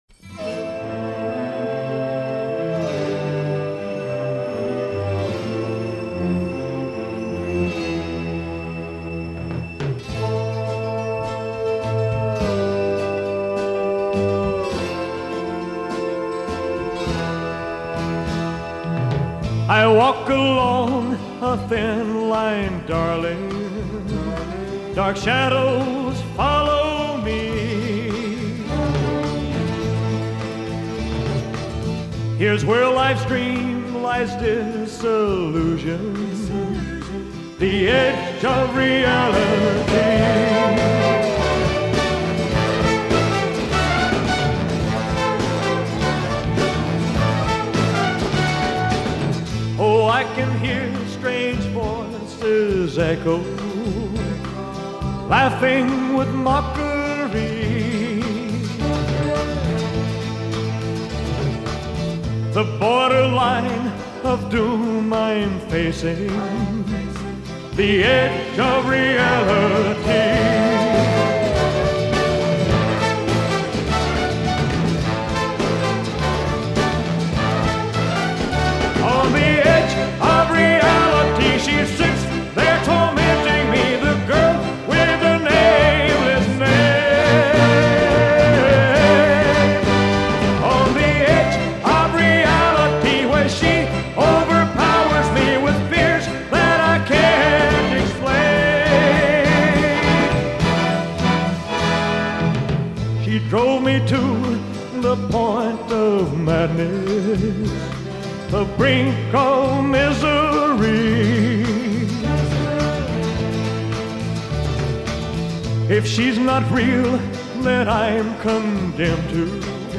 orchestral brass section